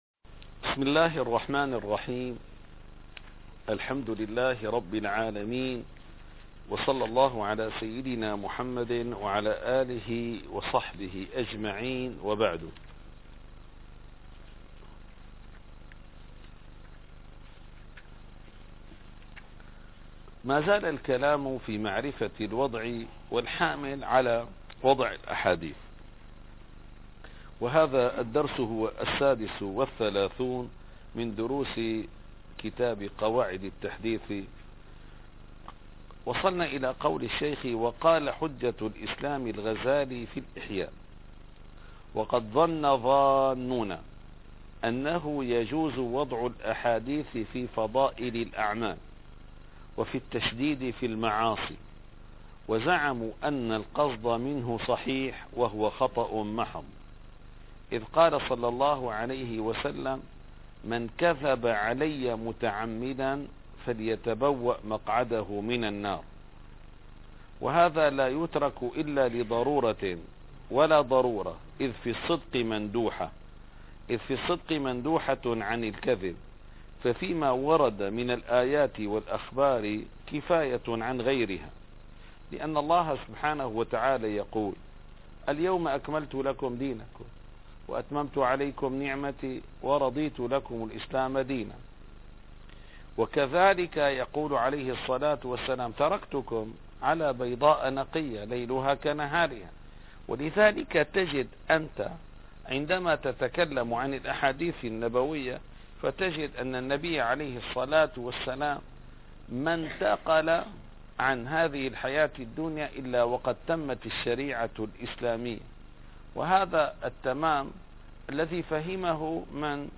- الدروس العلمية - قواعد التحديث من فنون مصطلح الحديث - 36- وقال حجة الإسلام ص157